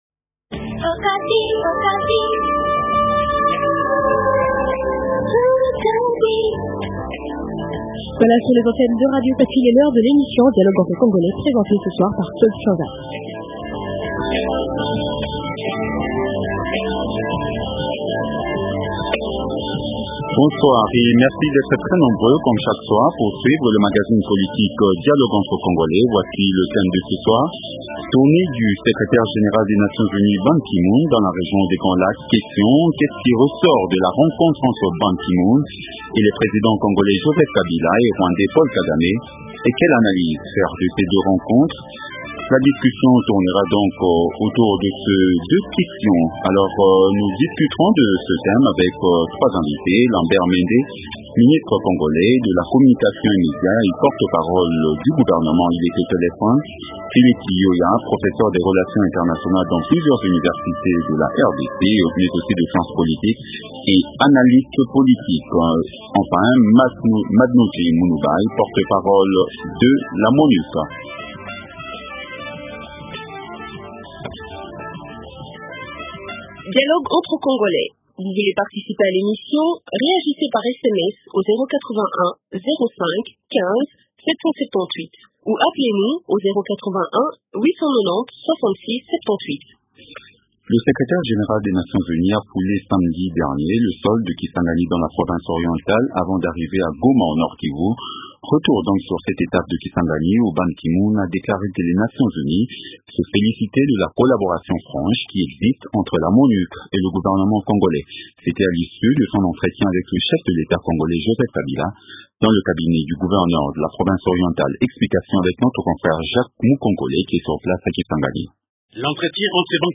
Invités -Lambert Mende, ministre congolais de la communication et medias.